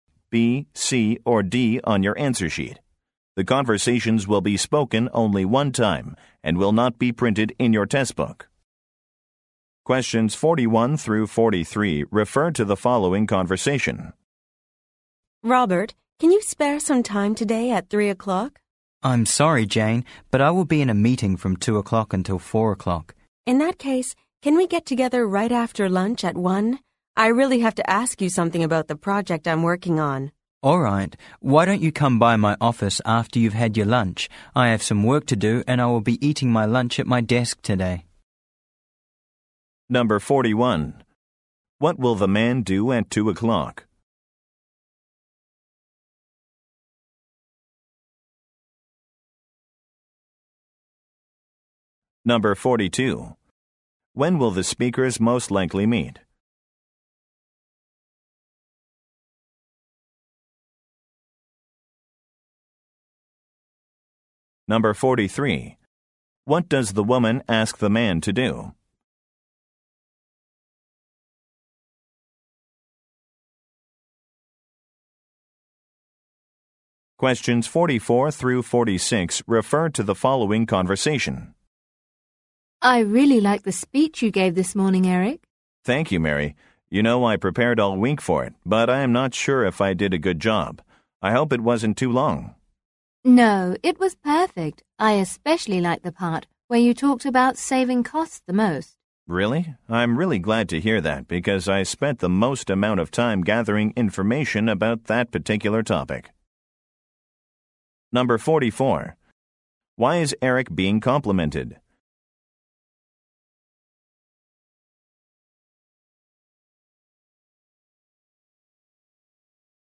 (A) Have lunch (B) Attend a meeting (C) Work on a project (D) Meet with the woman Correct 41-43 refer to the following conversation W: Robert, Can you spare some time today at three o’clock?